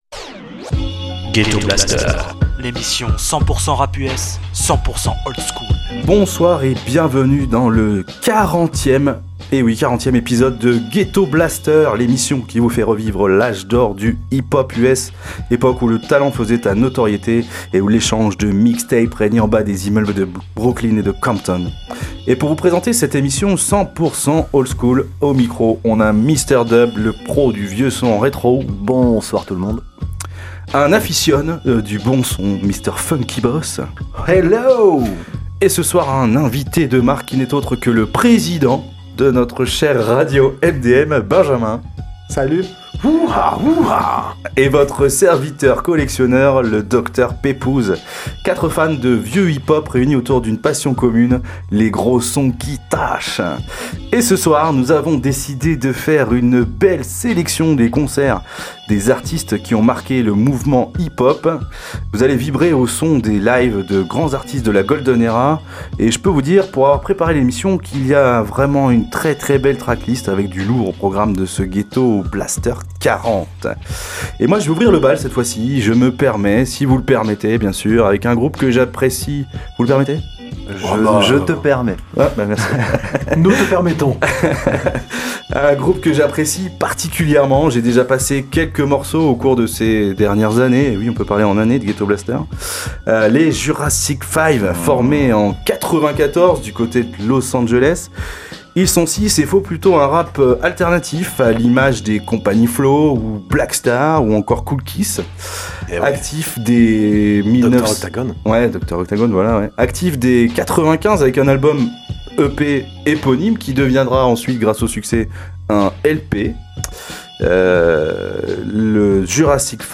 Le hip-hop US des années 80-90